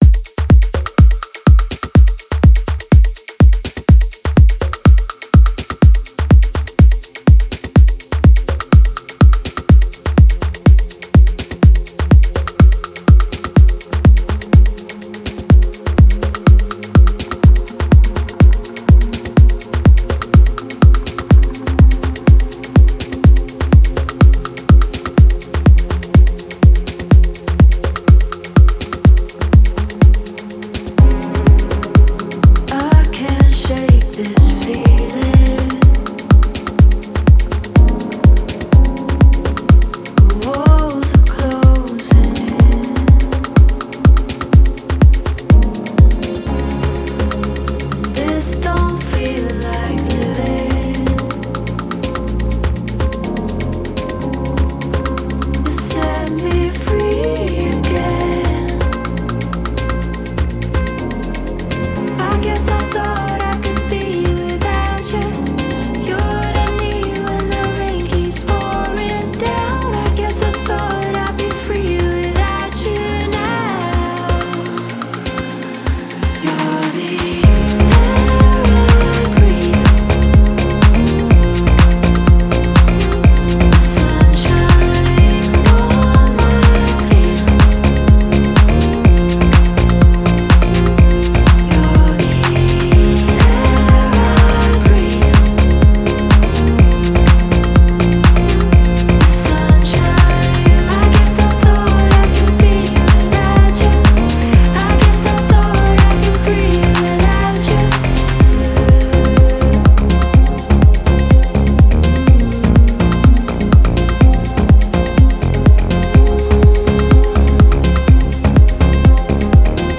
• Ремикс